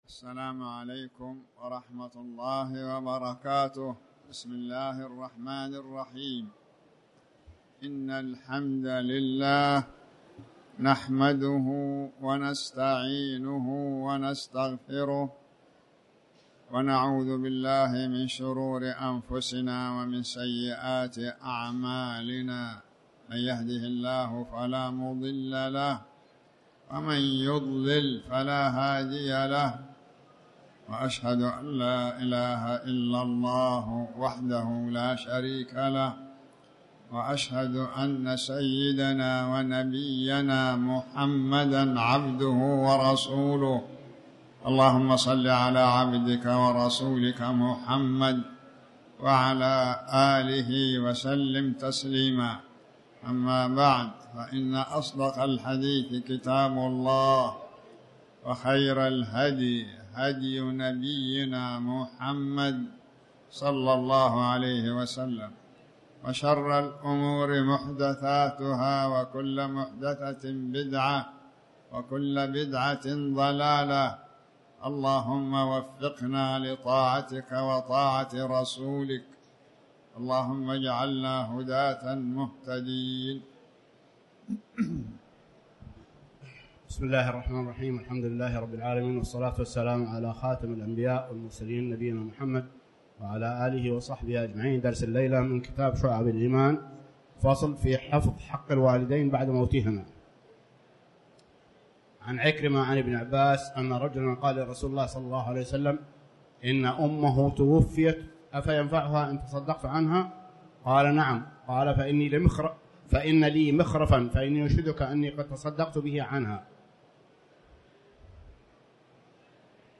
تاريخ النشر ٢٦ ربيع الأول ١٤٤٠ هـ المكان: المسجد الحرام الشيخ